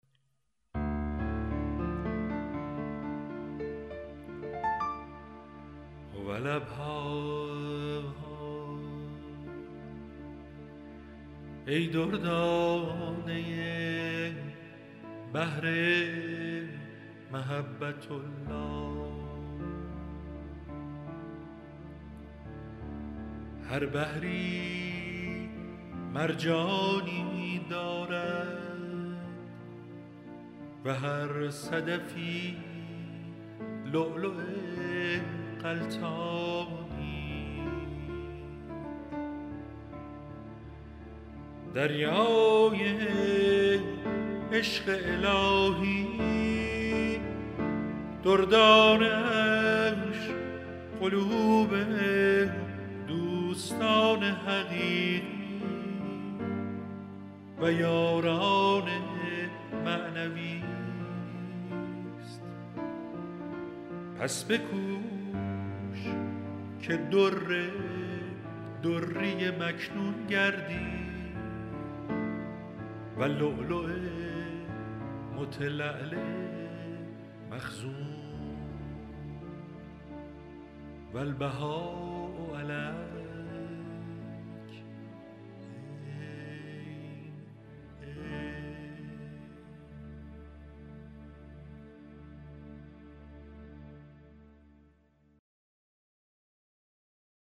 مناجات شماره 13 | تعالیم و عقاید آئین بهائی
مجموعه جدید مناجات های اطفال (صوتی)